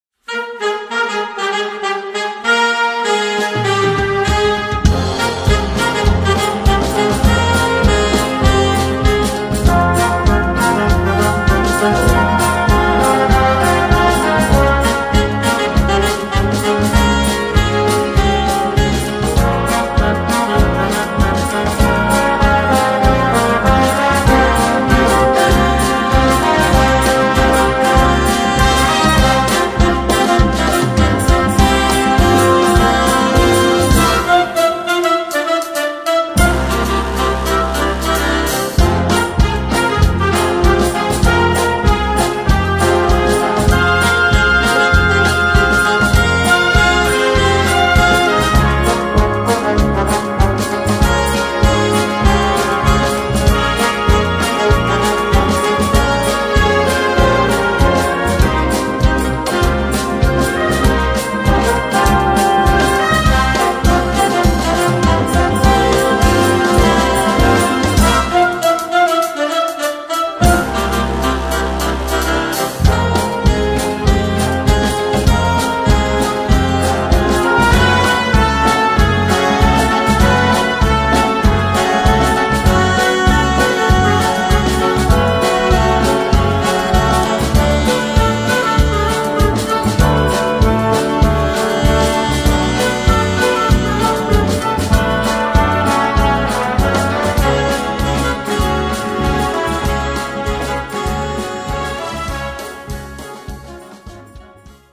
Filmmusik für Blaorchester
Besetzung: Blasorchester